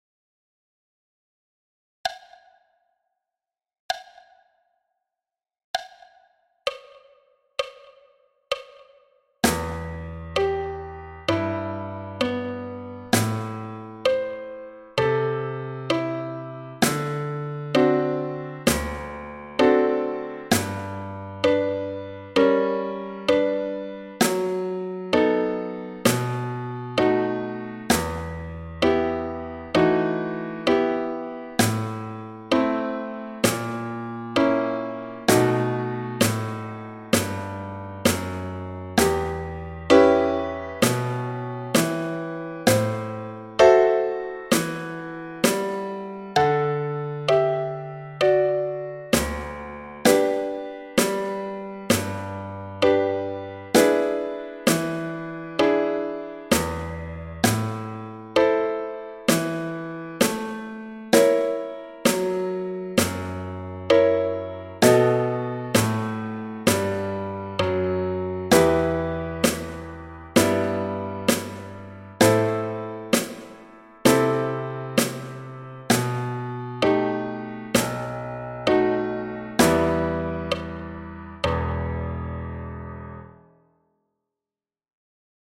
Etude 1- caisse et piano à 65 bpm
Etude-1-caisse-et-piano-a-65-bpm.mp3